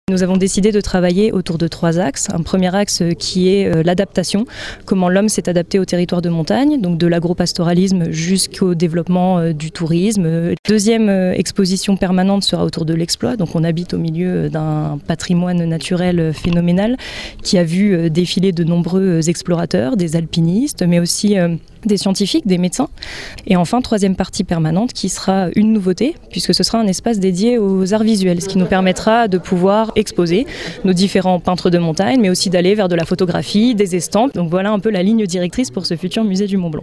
Trois expositions permanentes seront également proposées au public, comme nous l’explique Juliette Martinez, conseillère municipale déléguée à la culture, à la vie associative et à l’animation pour la ville de Chamonix.